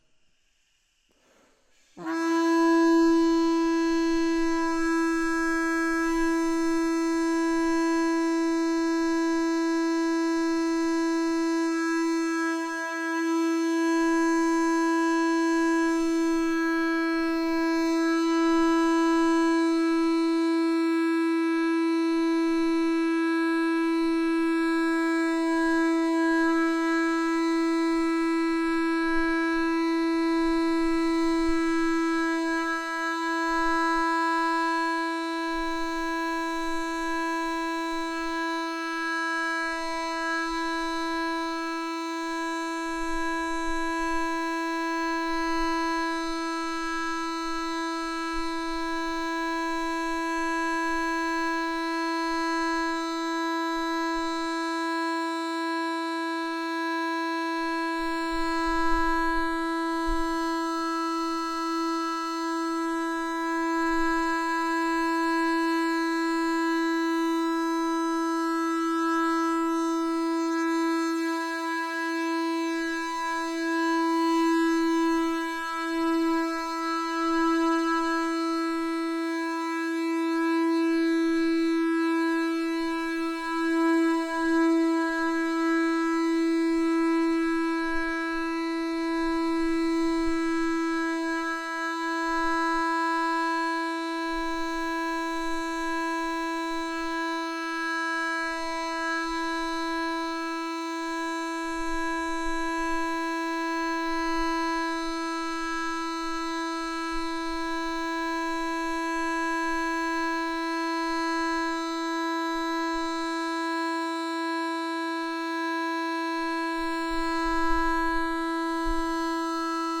2. Set a center note (a pedal note or a chord) – you can use this audio track here
--» PEDAL NOTE «--  and do this exercise with her, if you don't have any instruments available; otherwise, with a piano, a guitar or any other instrument capable of reproducing a continuous sound or a chord works well (avoiding the mode setting, omitting the 3rd chord interval).